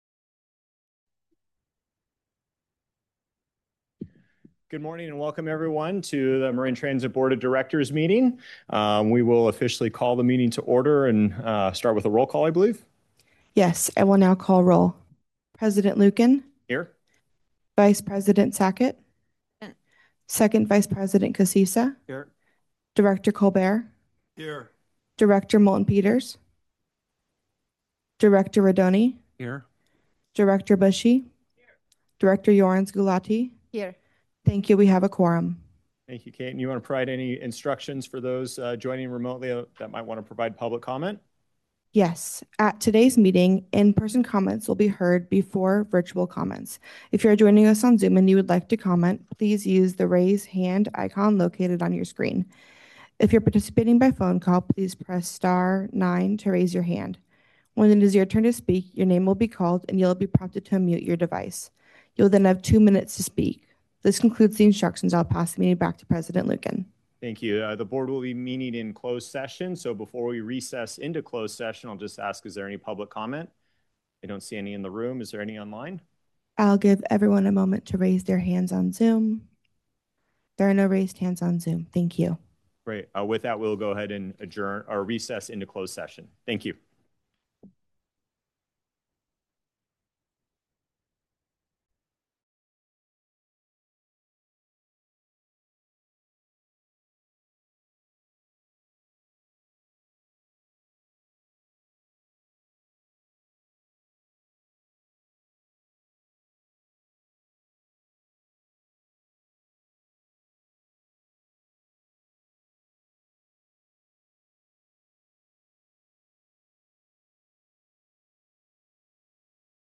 Members of the public can provide comment during open time and on each agenda item when the Board President calls for public comment. In-person comments will be heard before virtual comments.